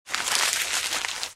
Звуки сворачивания бумаги
Скомканный